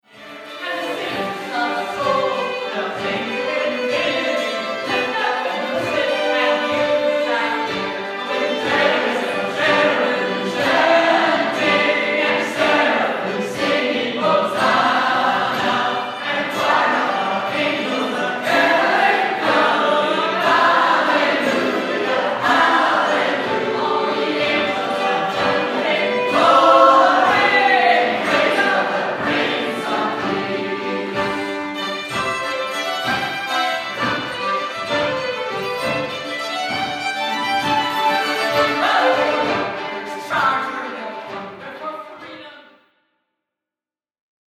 I hope you’ll give 30 seconds to the attached audio clip [below] from that old December night; you’ll hear the stomp and tickle you yourself are so familiar with, and our voices, chasing our eager leader, following her with hope and heart into the long passage through night.
chariots-chanting.mp3